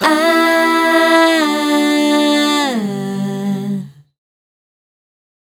Ahh 086-G.wav